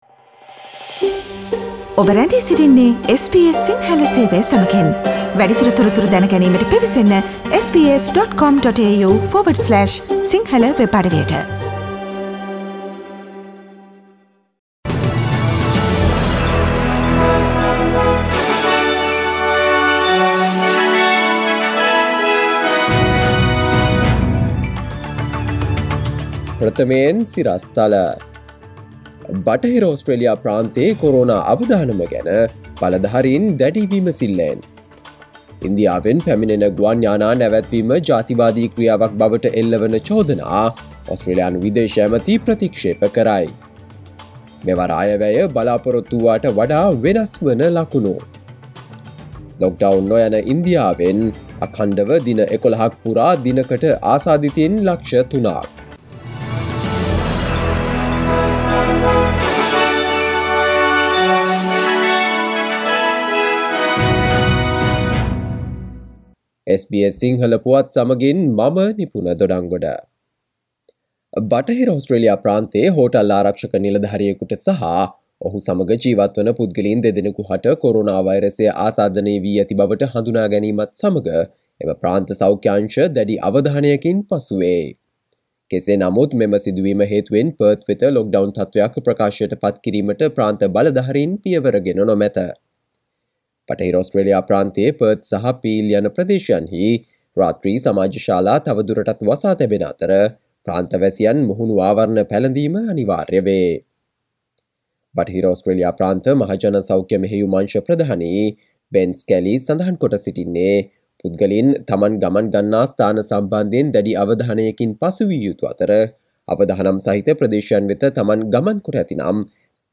Here are the most prominent Australian and Sri Lankan news highlights from SBS Sinhala radio daily news bulletin on Monday 03 May 2021.